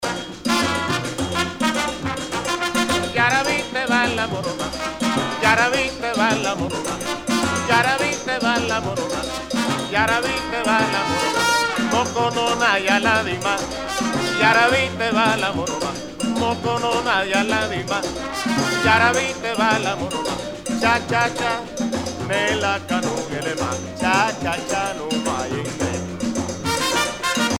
danse : pachanga (Cuba)
Pièce musicale éditée